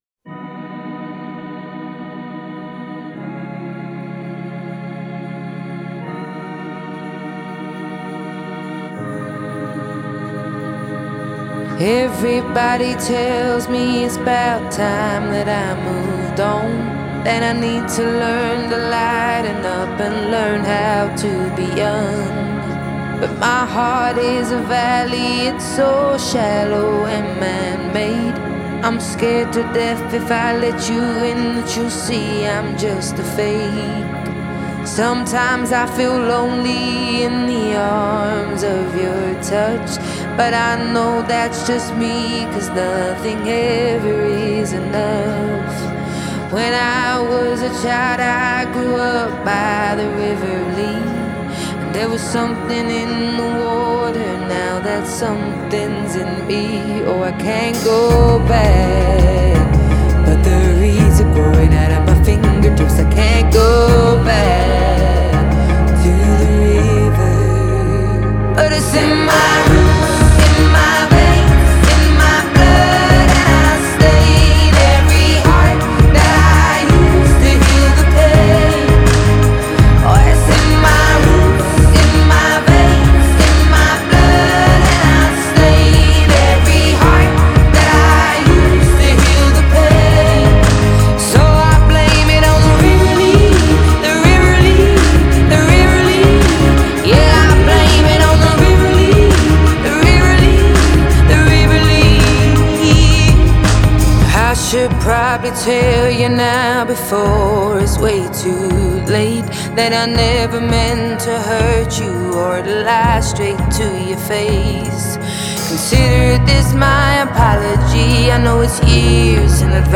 Genre: Pop,Blues